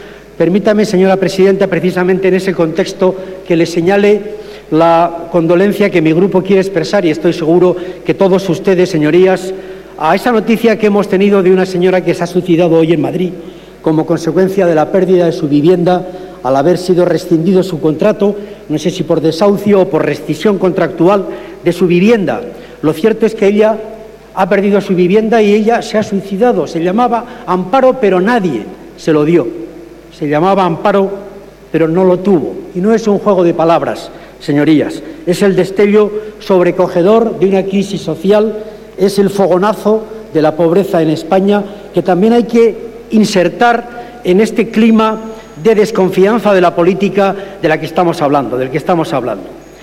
Fragmento de la intervención en el pleno del 17/09/2013 de Ramón Jáuregui rindiendo homenaje a la mujer que se ha suicidado en Madrid tras perder su vivienda